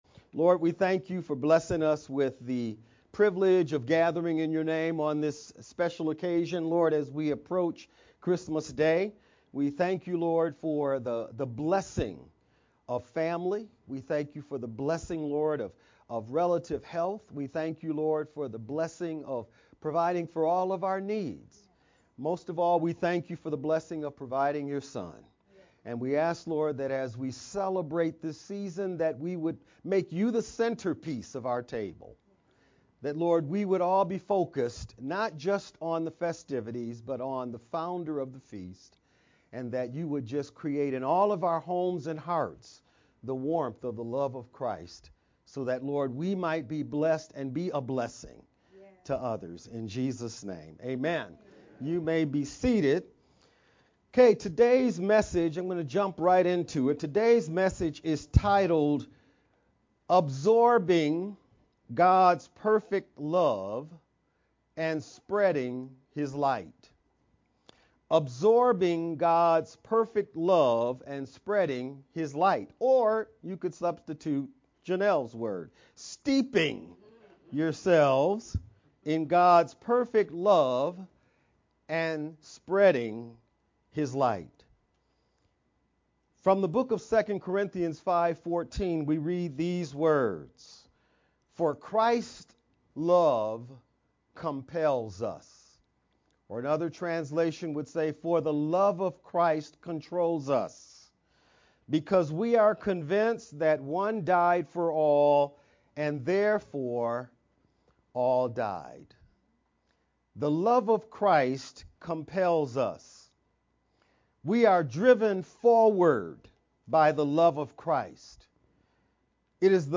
Dec-22nd-Sermon-only-edited_Converted-CD.mp3